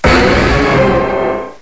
cry_not_mega_charizard_y.aif